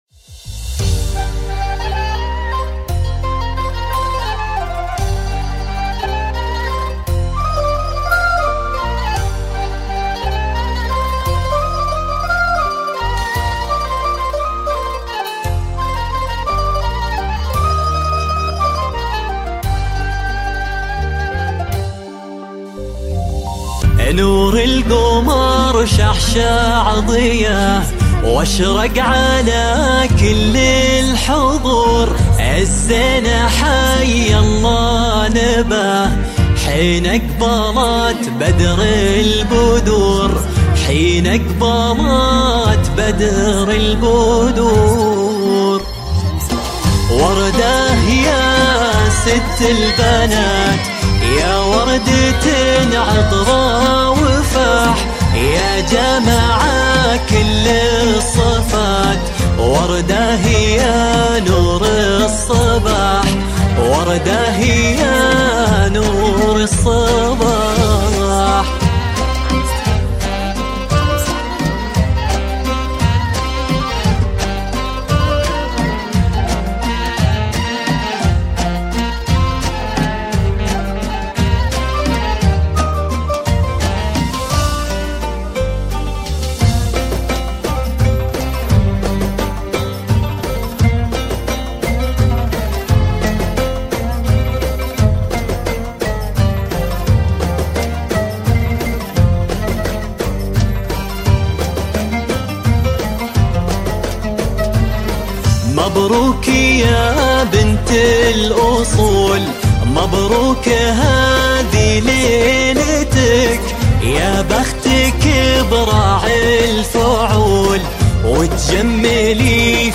زفات موسيقى